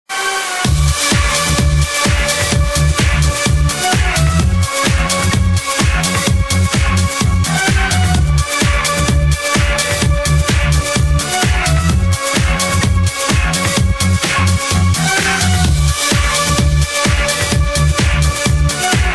/128kbps) Описание: Немного смахивает на тему из 9 роты.